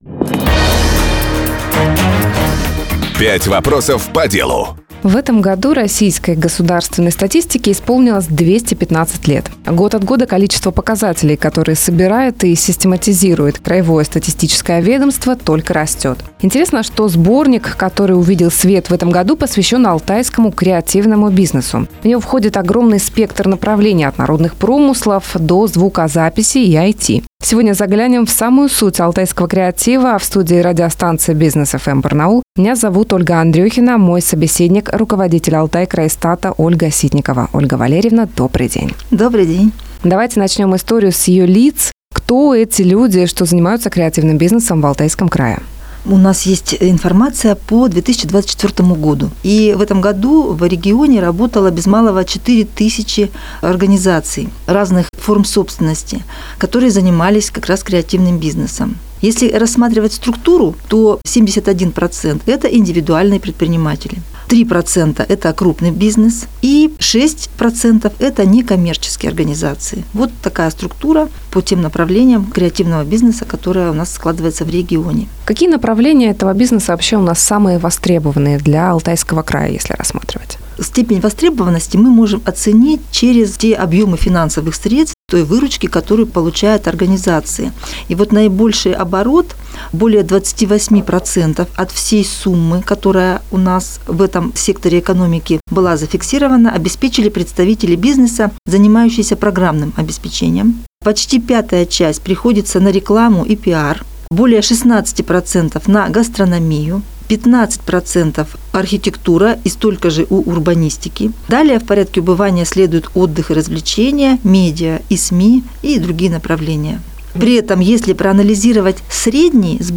Как развивается этот сектор экономики в регионе — в интервью Business FM (Бизнес ФМ) Барнаул рассказала руководитель Алтайкрайстата Ольга Ситникова.